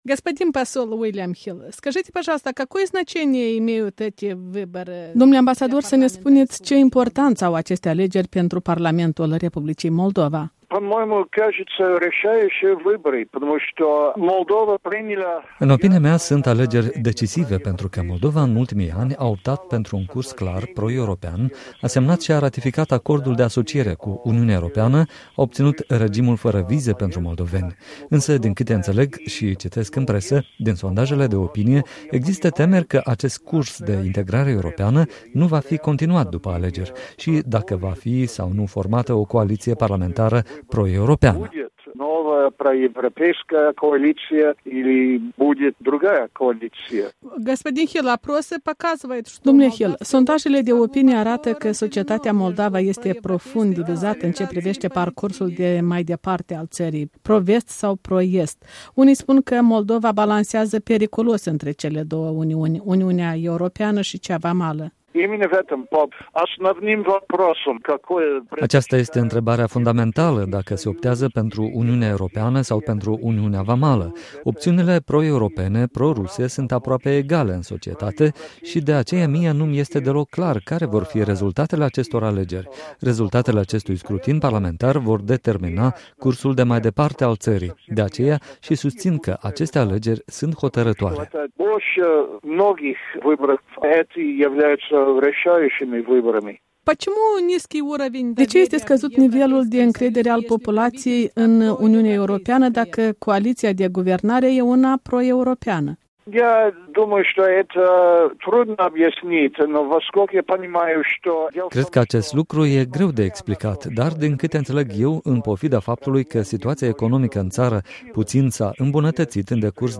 În dialog cu ambasadorul William Hill, fostul şef al Misiunii OSCE în R. Moldova